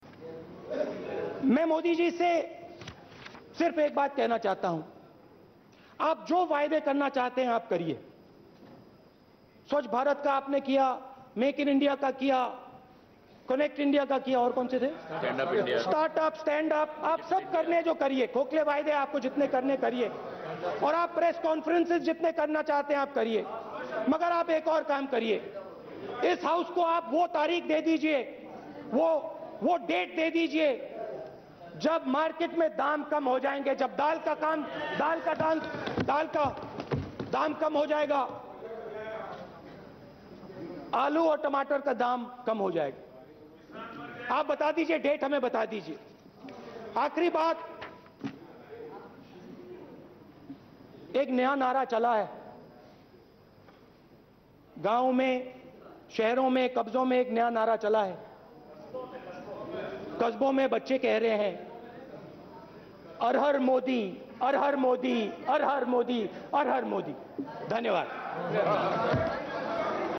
गुरुवार को देश की संसद में बोलते हुए कांग्रेस उपाध्यक्ष राहुल गांधी ने तेल की बढ़ती कीमतों और बढ़ती मंहगाई की दर के बारे में बात की.